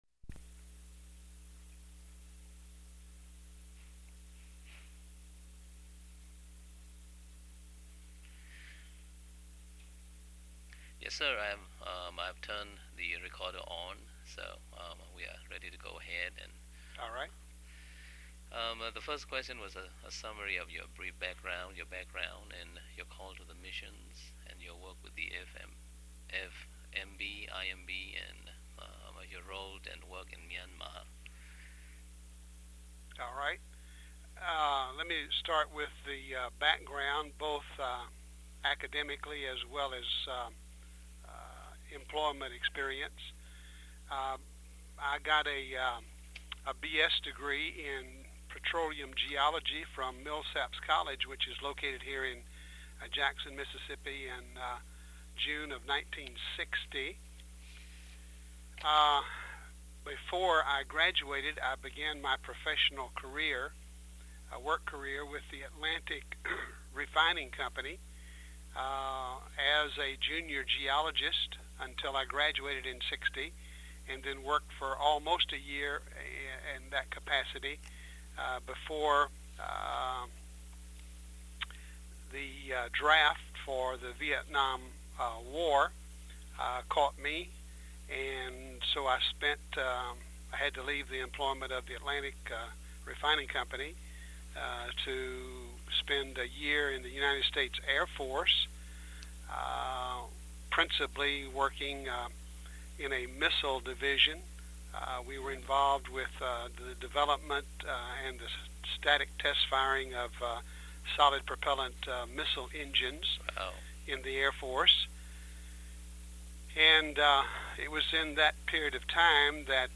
Elementos